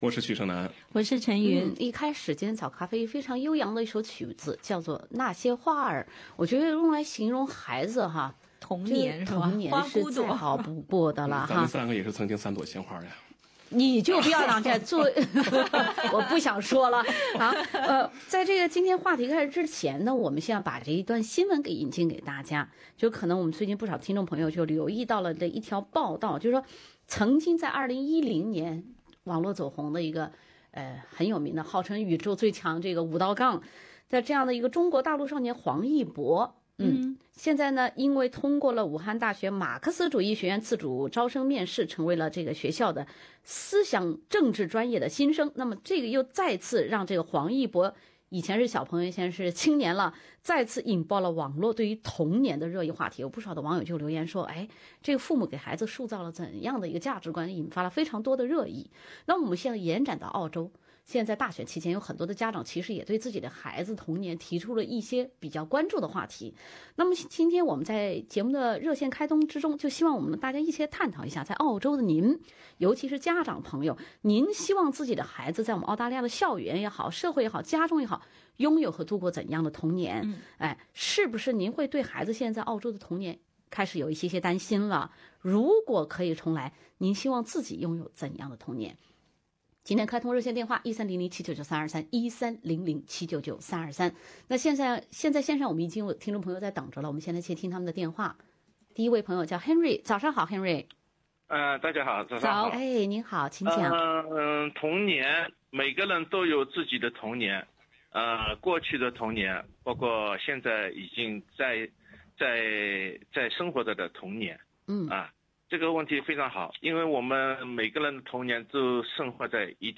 本期《早咖啡》，听众分享送给孩子一个怎样的童年。